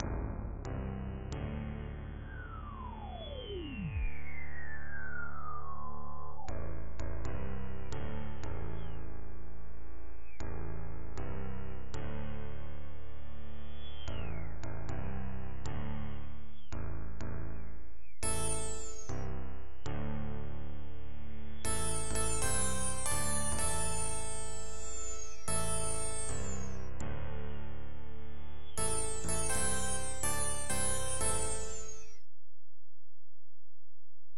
This emulation is that of the prophet 5 with added realtime effects.
Sample #1: memory 55, excess filter, mild chorus.
Sample #2: memory 1, filter, mild chorus.
This was played from my laptop QWERTY so there are some clicks and faults.